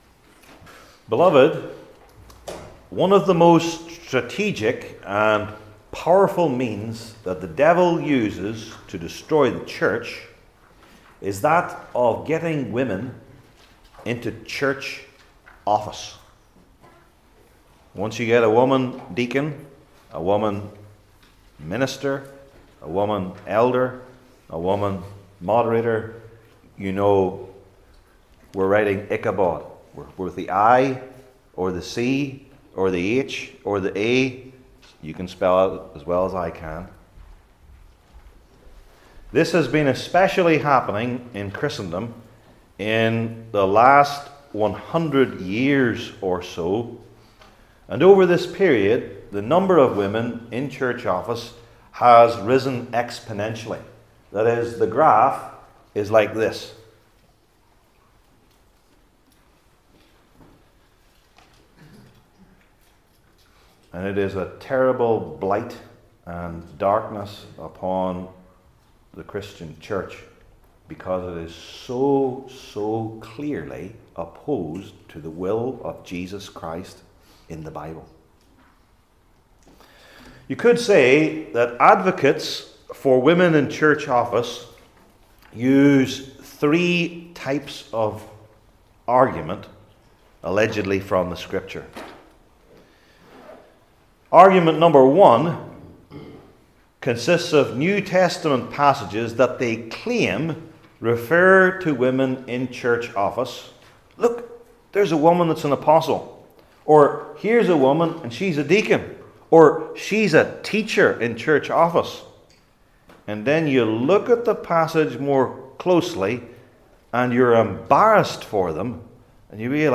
Old Testament Sermon Series I. Their Specific Identity II.